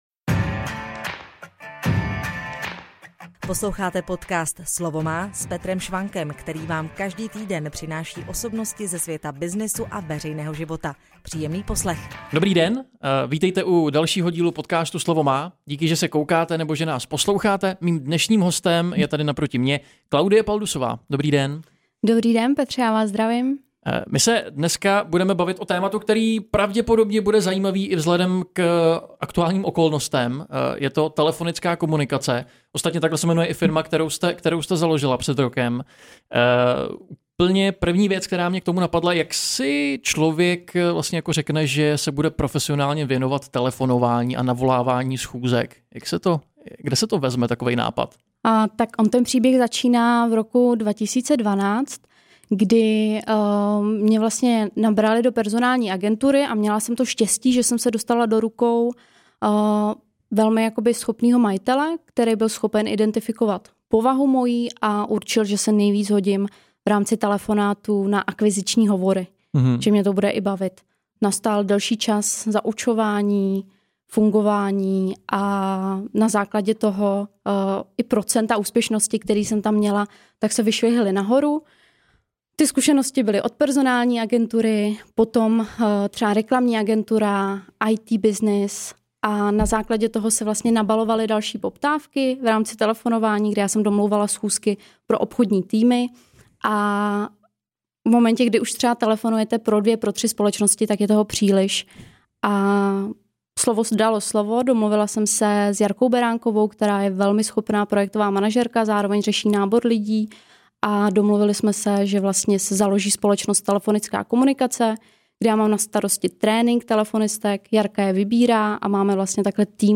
V rozhovoru například prozradí některé tipy, které sama při volání používá. Anebo se dozvíte, jak se dělí obchodníci a kteří jsou vhodnější na jakou část obchodního procesu.